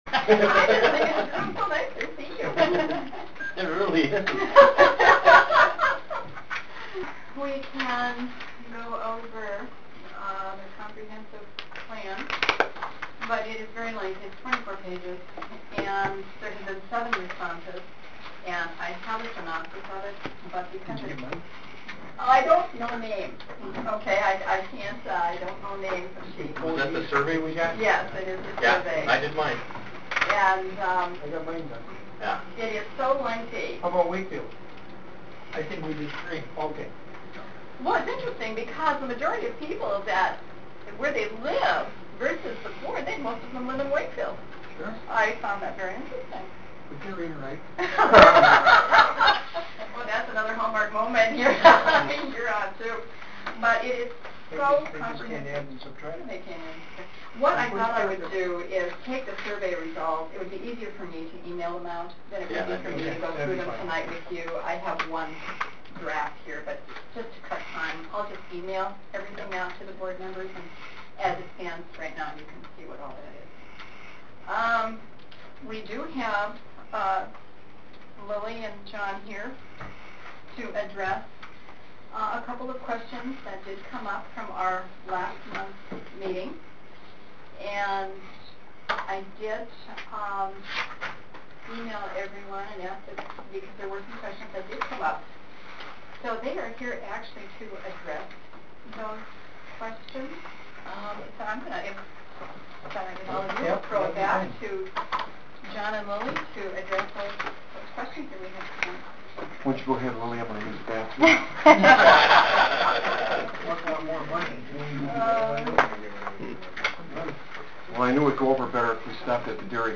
E.D.C. Ponders Wetland Mitigation Issue Bessemer October 24th, 2008 The Gogebic Economic Development Commission met at the M.S.U. offices in Bessemer yesterday.
Those who did show up for the meeting did hold an unofficial meeting that lasted one and a quarter hour.